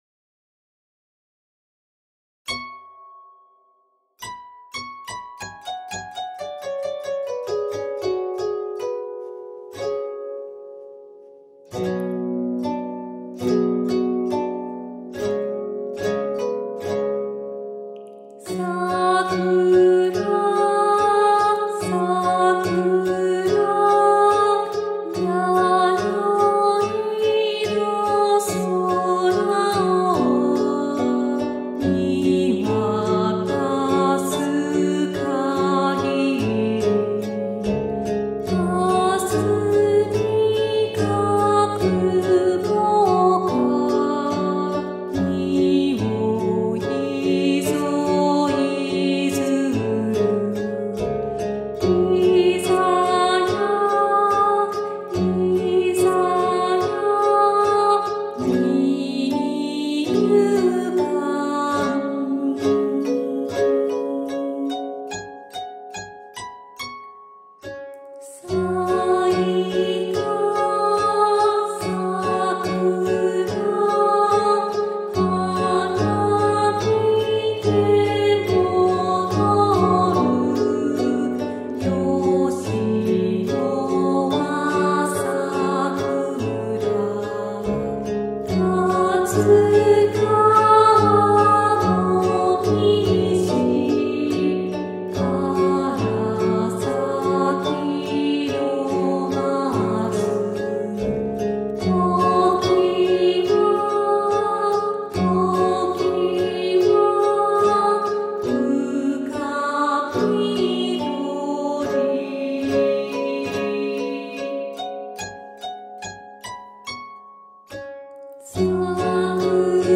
Japanese Songs
Japanese Old Folk Song